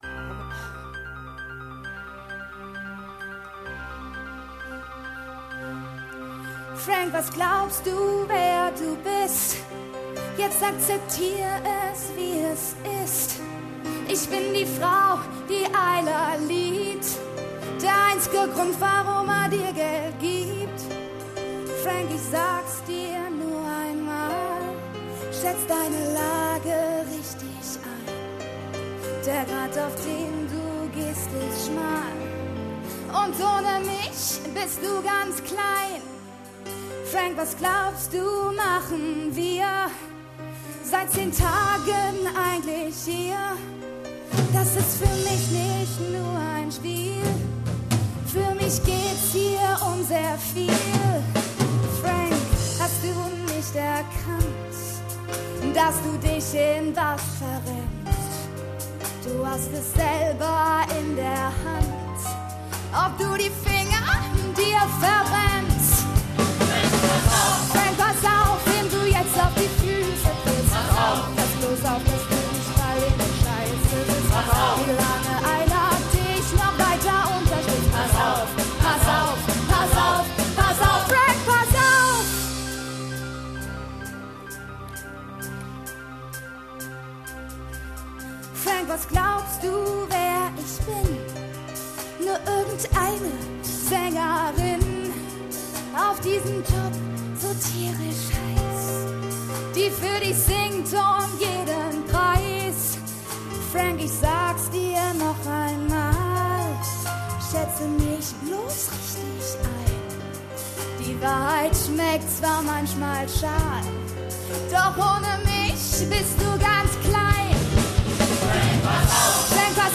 Orchesterarbeit, Chorarbeit, Tanzarbeit und die Arbeit am Bühnenbild nahmen immer konkretere Gestalt an.